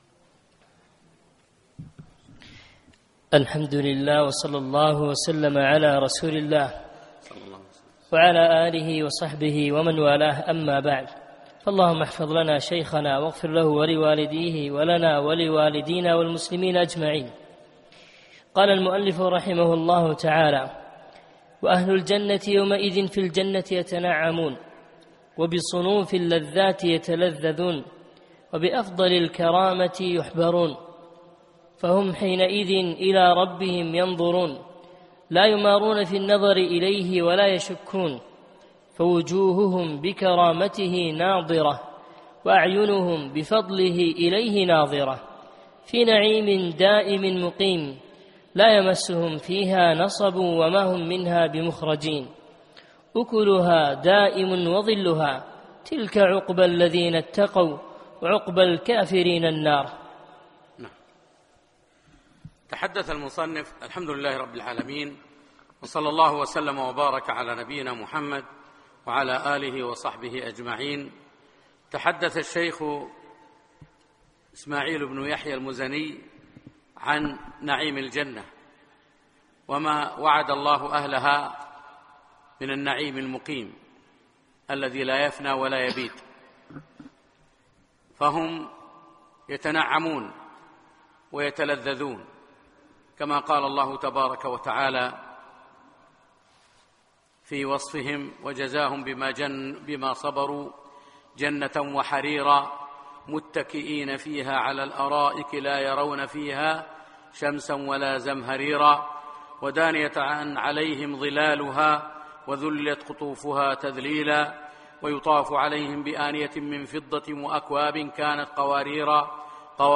الأثنين 3 4 2017 بعد صلاة العشاء مسجد صالح الكندري صباح السالم